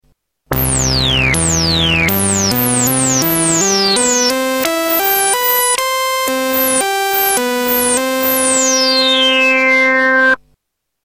Tags: Sound Effects Orca Demos FXpansion Orca FXpansion Soft Synth